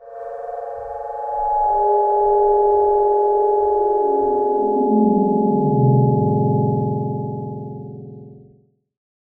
cave5.ogg